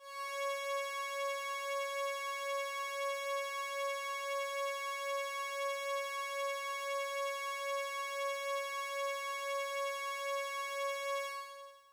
Roland Juno 6 Open Pad " Roland Juno 6 Open Pad C6 ( Open Pad851278PQS)
Tag: CSharp6 MIDI音符-85 罗兰朱诺-6- 合成器 单票据 多重采样